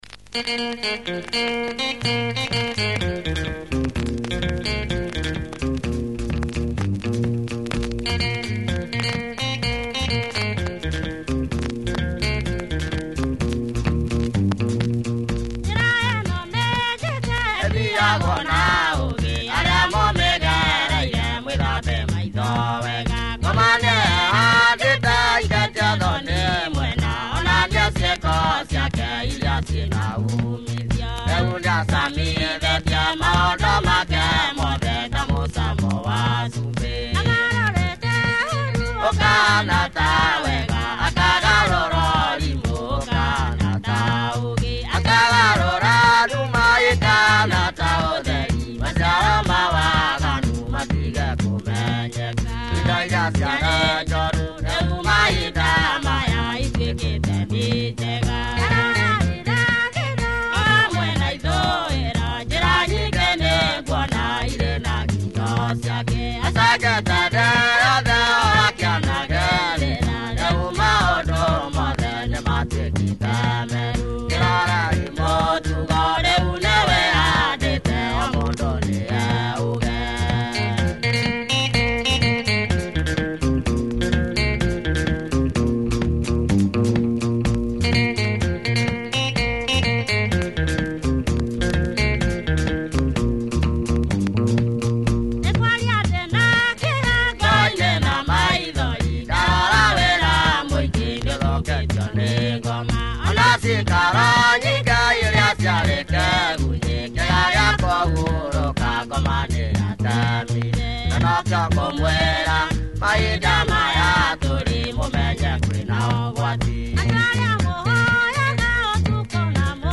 Killer tribal Kiuyu groove on this, club spin.